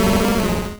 Cri d'Ossatueur dans Pokémon Rouge et Bleu.